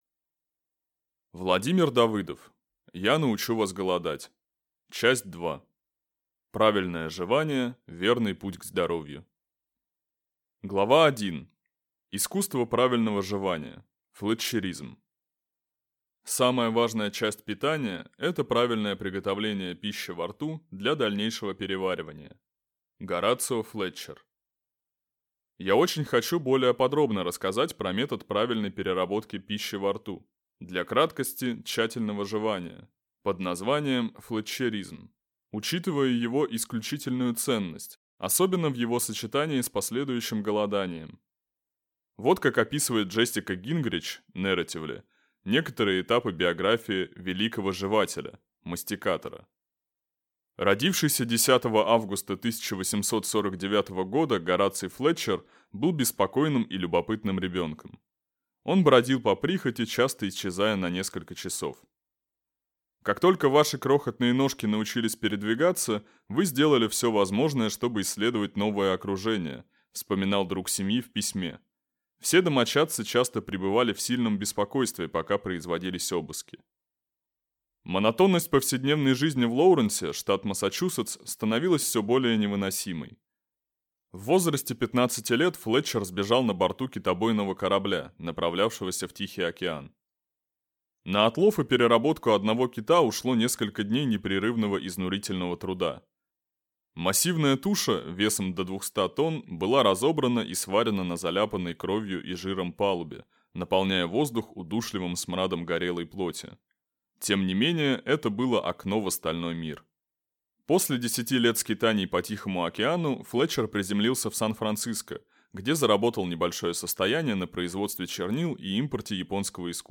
Аудиокнига Я научу вас голодать. Часть 2. Правильное жевание – верный путь к здоровью | Библиотека аудиокниг